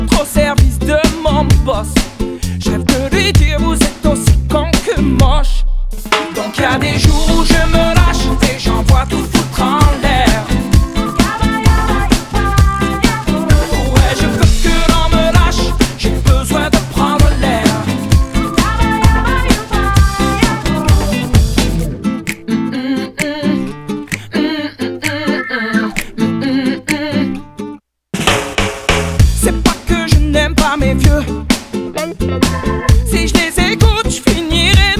French Pop
Жанр: Поп музыка / Рок / Соундтрэки / Альтернатива